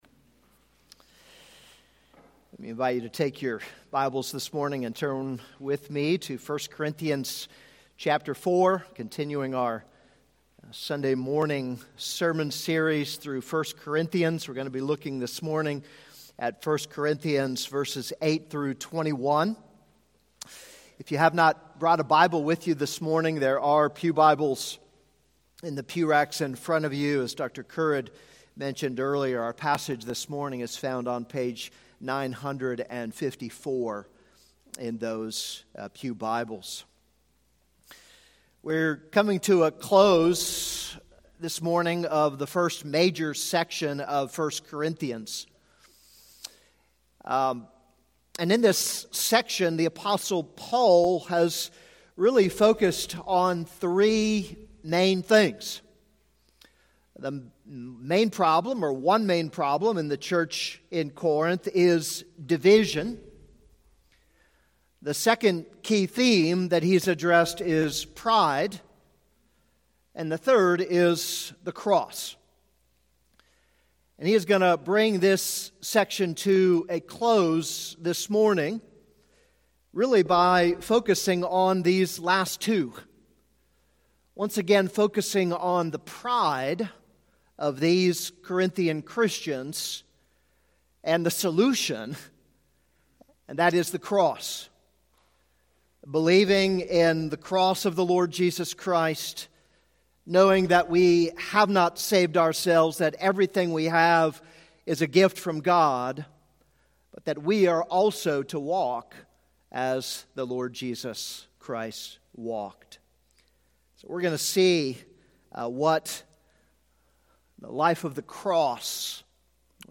This is a sermon on 1 Corinthians 4:8-21.